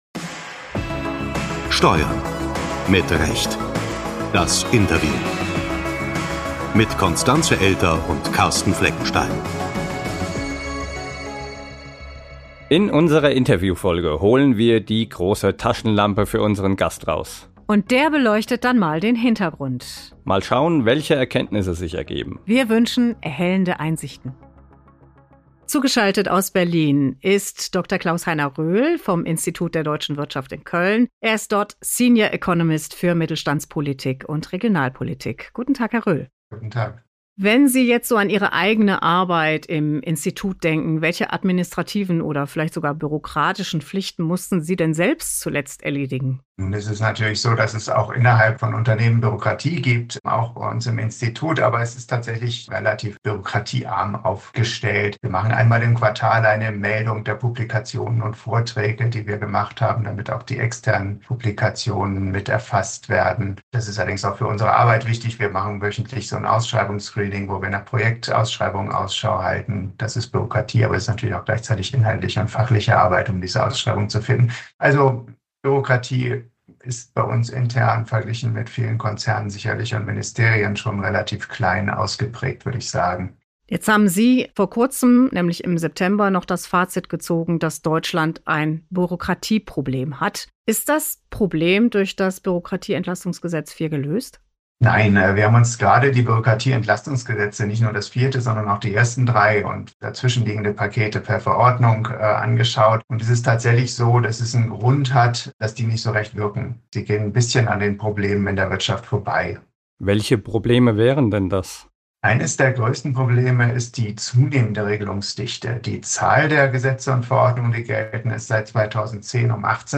Bürokratieentlastungsgesetz IV: Das Interview ~ Steuern. Mit Recht! Der DATEV-Podcast Podcast